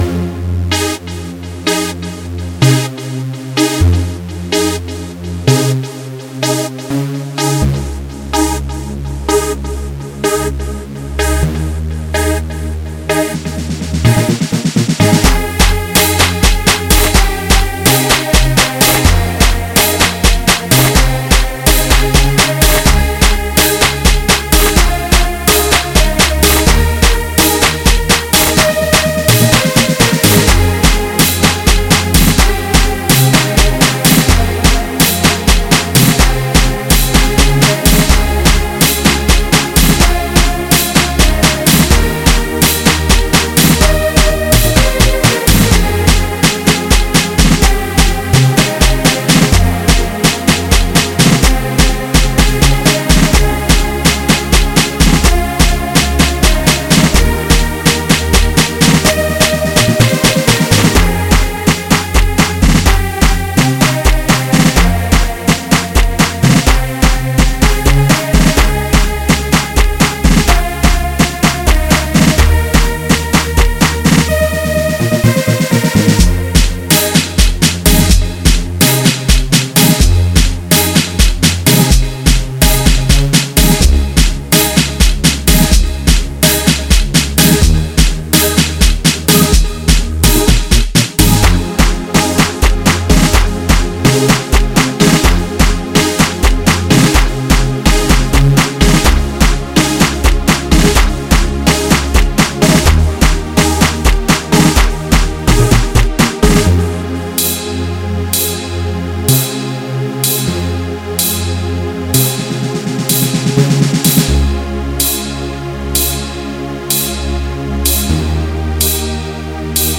03:56 Genre : Bolo House Size